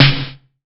SNARE 067.wav